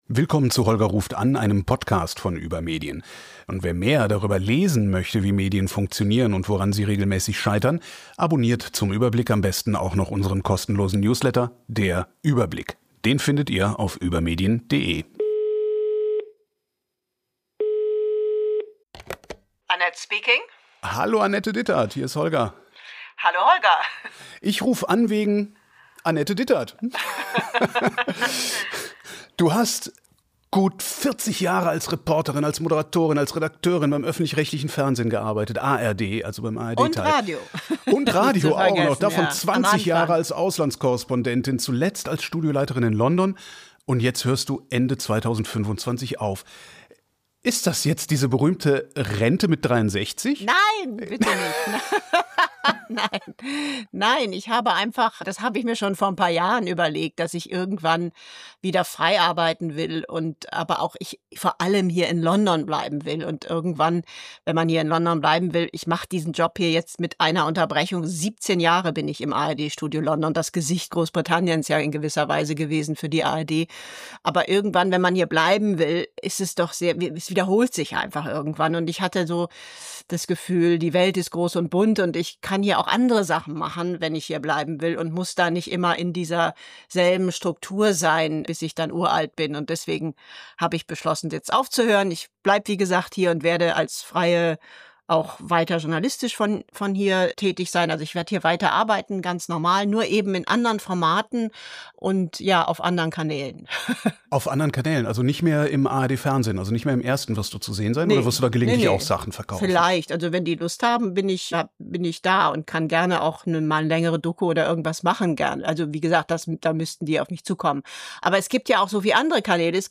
Gast: Annette Dittert, London-Korrespondentin der ARD Sie war Korrespondentin in Russland, Polen, den USA – aber kein Land hat sie so sehr begeistert wie Großbritannien und insbesondere die Hauptstadt London.